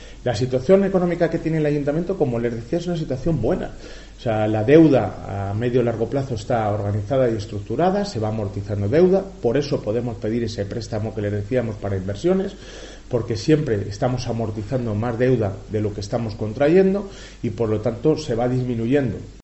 Alcalde de Ávila. "La situación económica del Ayuntamiento es buena"